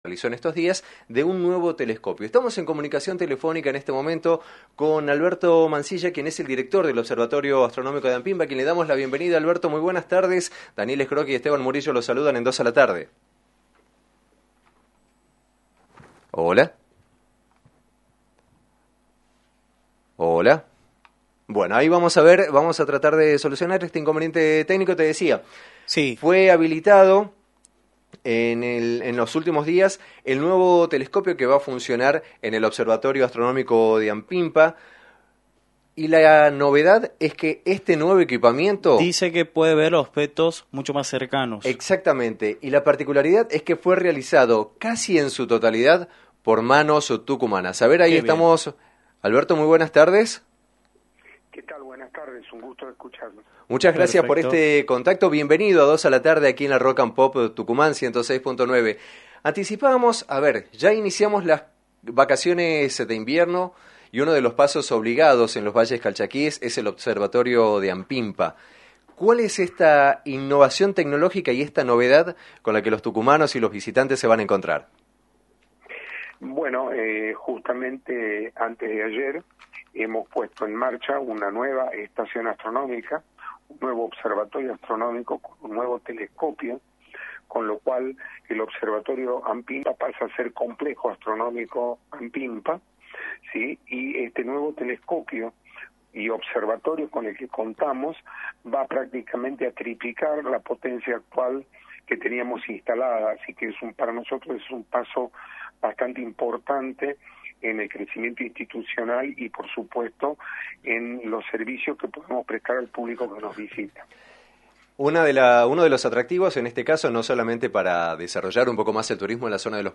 diálogo vía telefónica en «Dos a la Tarde» para el aire de la Rock&Pop FM 106.9